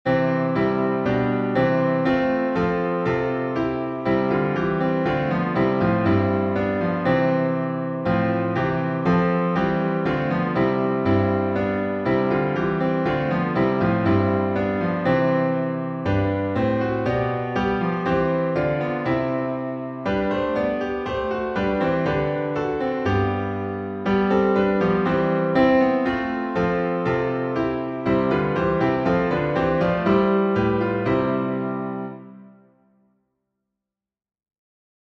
Key signature: C major (no sharps or flats) Time signature: 4/4